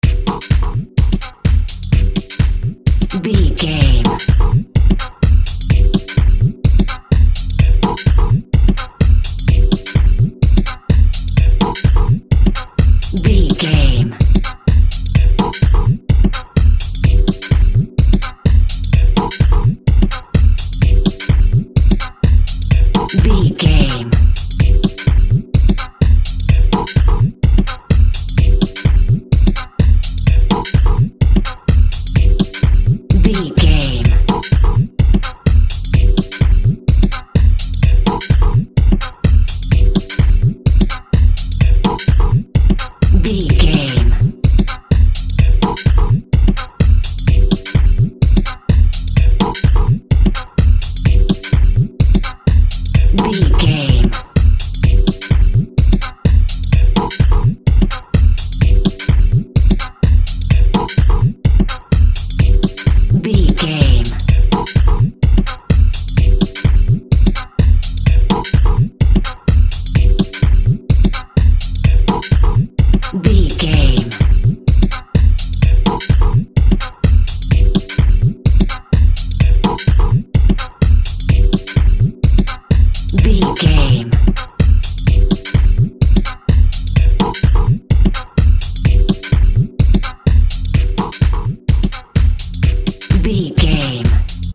Fusion Techno.
Atonal
energetic
hypnotic
industrial
drum machine
synthesiser
synth lead
synth bass